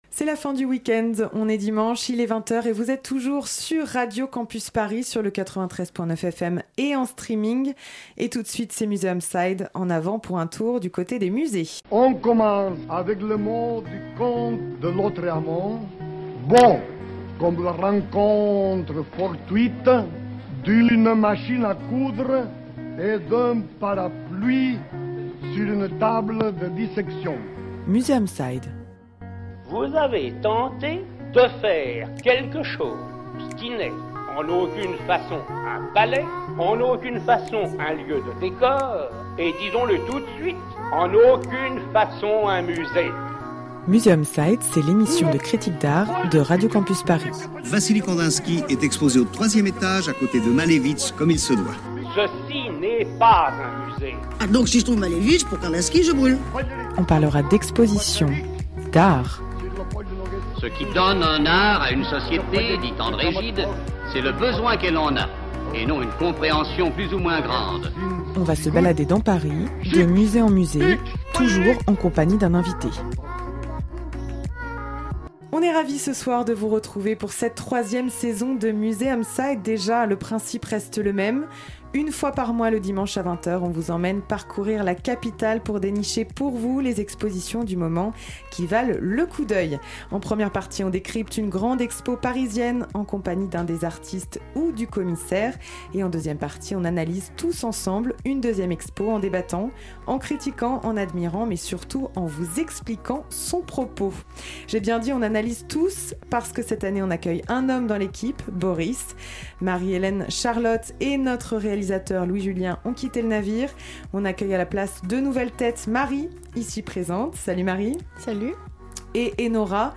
En première partie, on décrypte une grande expo parisienne en compagnie d'un des artistes ou du commissaire et en deuxième partie, on analyse tous ensemble une deuxième expo en débattant, en critiquant, en admirant mais surtout en vous expliquant son propos.